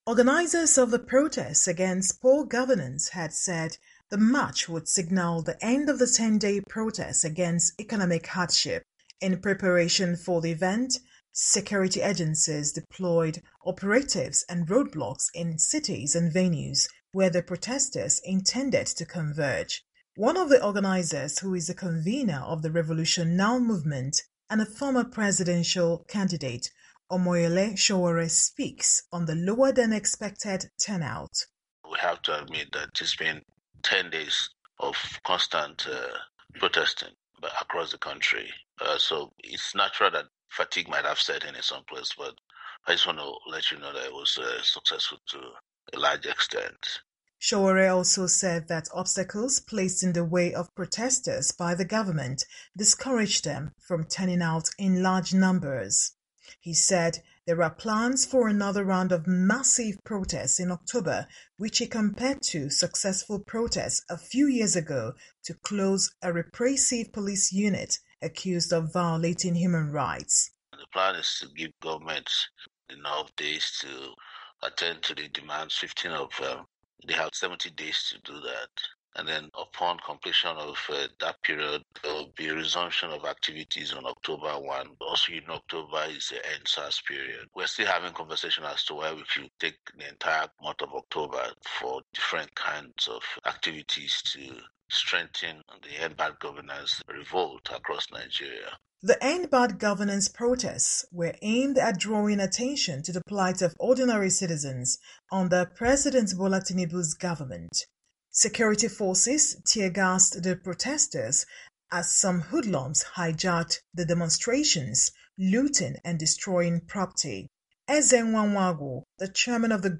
In Nigeria, the grand finale of the End Bad Governance protests which started on August 1 ended Saturday amid low turnouts. Our reporter in Abuja has more